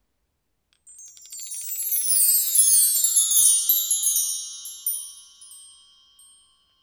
One Shots
Original creative-commons licensed sounds for DJ's and music producers, recorded with high quality studio microphones.
recorded studio chimes 2.wav
recorded_studio_chimes_2_FXr.wav